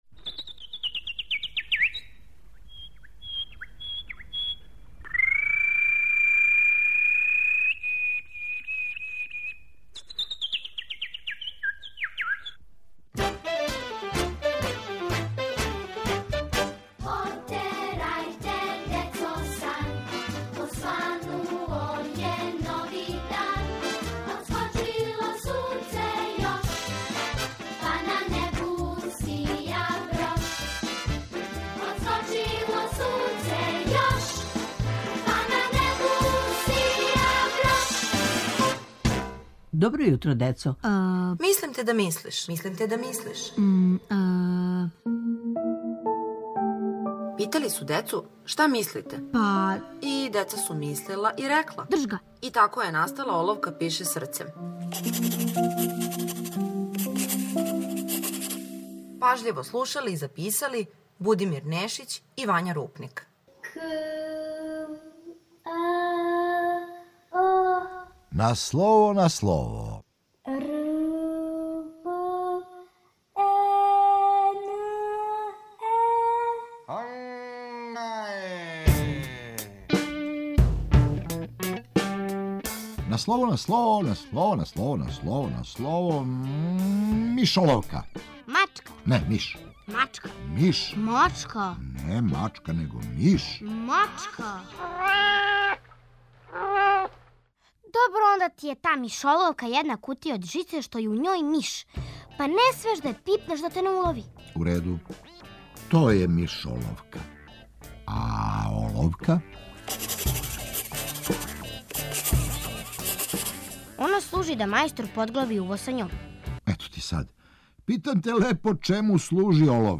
Питали су децу: "Шта мислите?" И деца су мислила, мислила и рекла! И тако је настала "Оловка пише срцем".